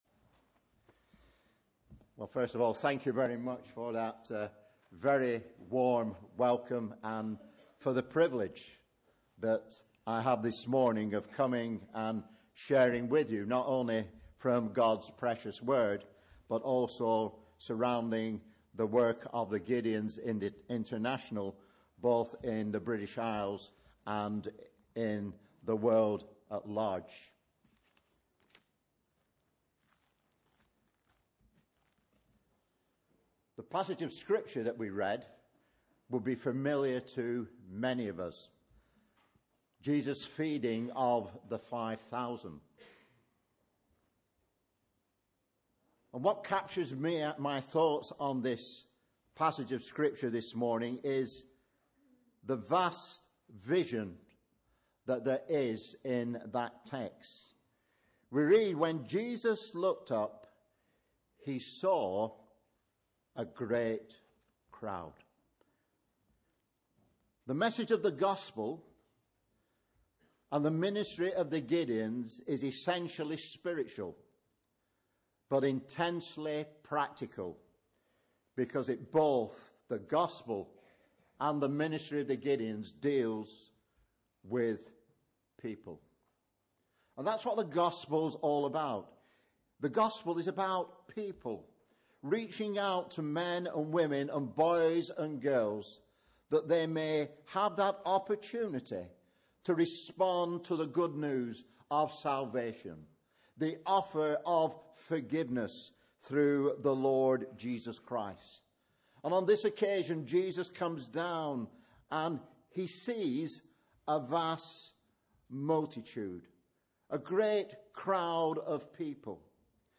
Sunday 11th October 2015 – Morning Service